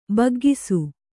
♪ baggisu